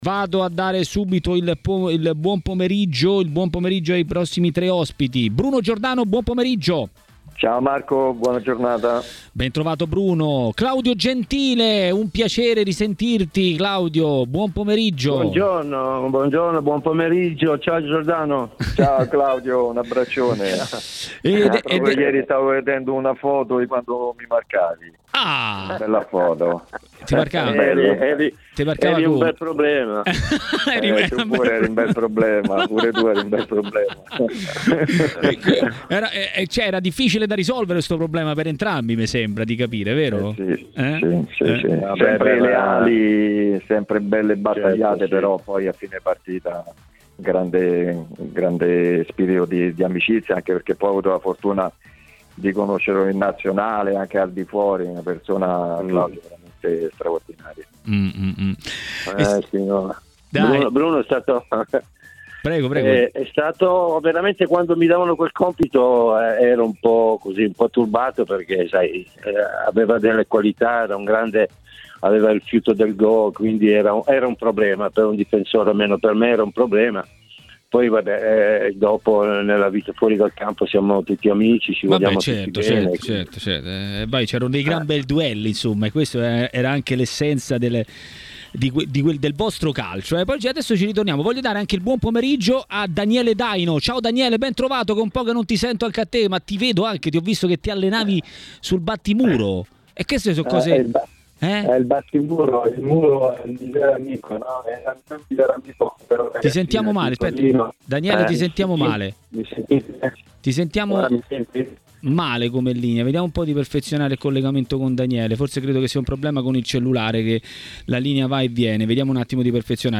L'ex calciatore e tecnico Claudio Gentile ha detto la sua a TMW Radio, durante Maracanà, su diversi temi.